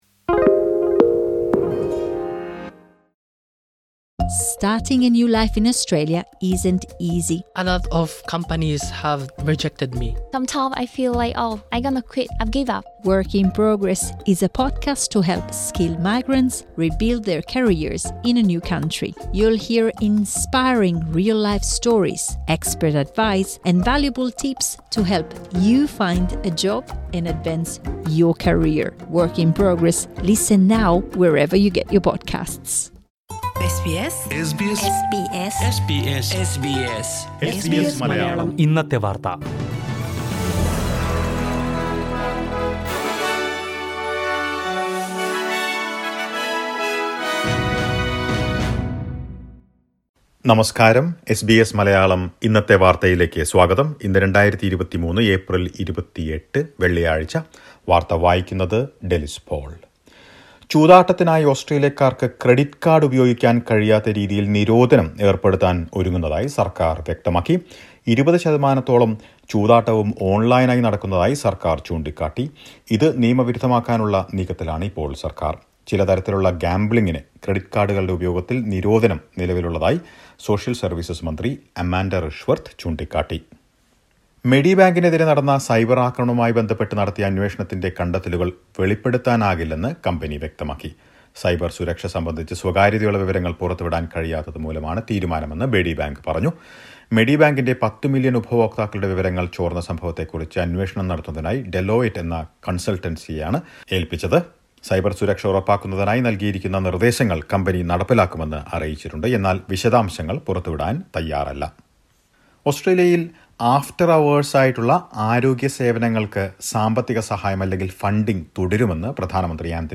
2023 ഏപ്രിൽ 28ലെ ഓസ്ട്രേലിയയിലെ ഏറ്റവും പ്രധാന വാർത്തകൾ കേൾക്കാം..